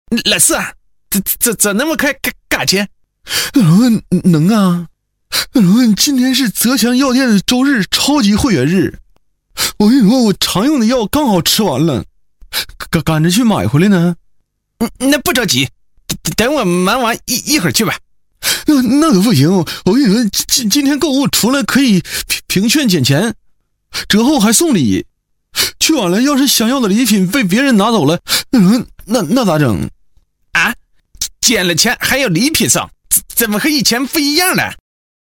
【男5号模仿】刘能、赵四
【男5号模仿】刘能、赵四.mp3